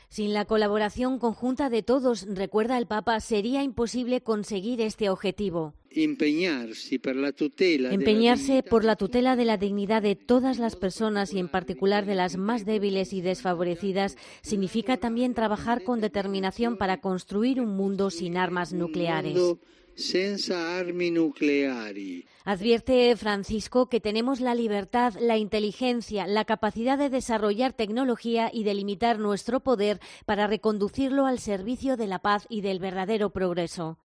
El Pontífice ha hecho estas reflexiones después del rezo del Ángelus dominical y desde la ventana del palacio apostólico vaticano.